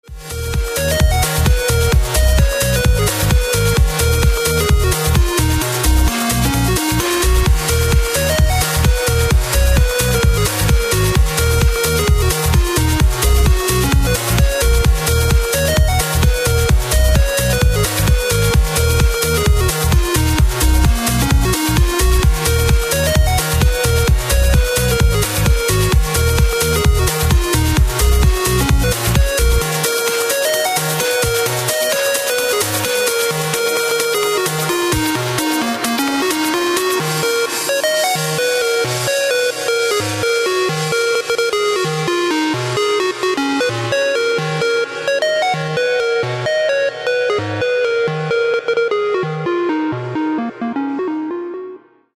• Качество: 128, Stereo
громкие
dance
Electronic
EDM
без слов
Trance